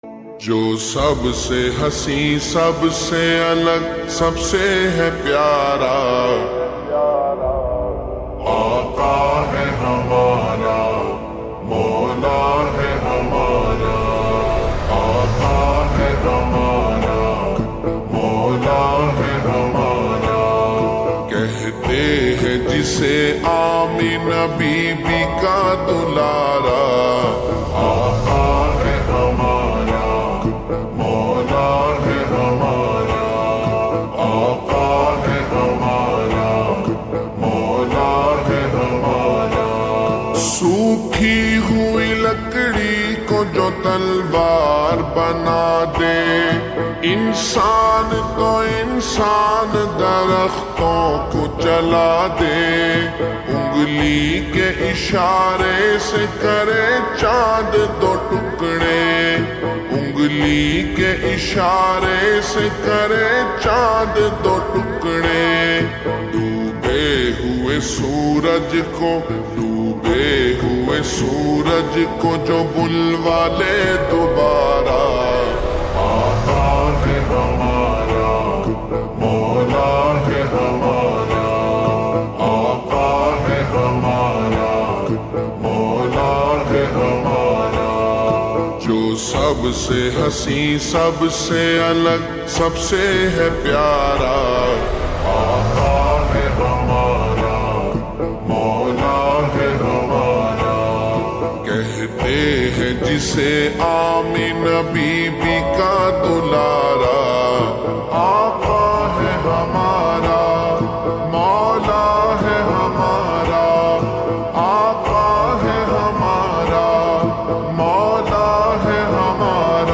Naat
Slowed Reverb